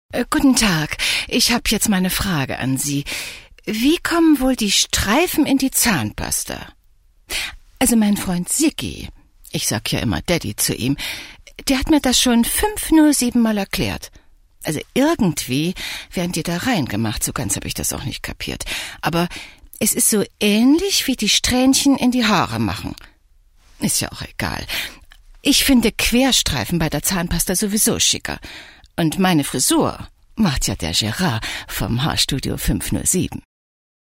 Warme Alt-Stimme, Kompetenz in der Stimme. Reife Stimme.
Sprechprobe: Sonstiges (Muttersprache):
German female voice over talent